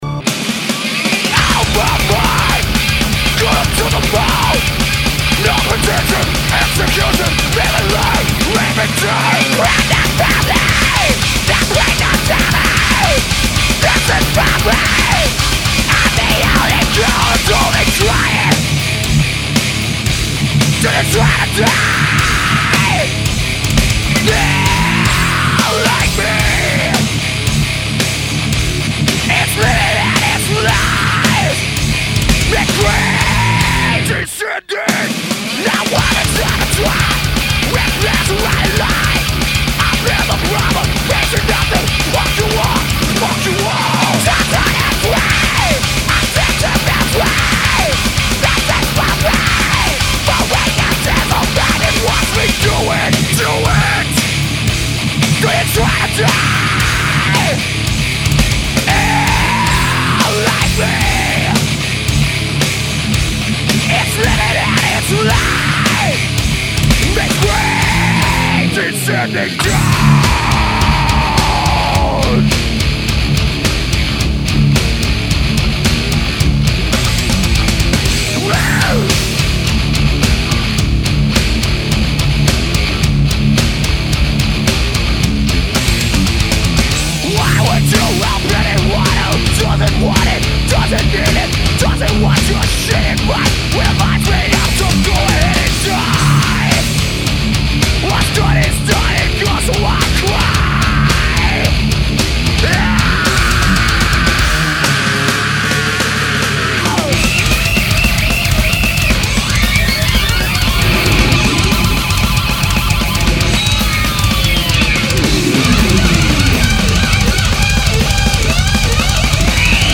Genre: Thrash Metal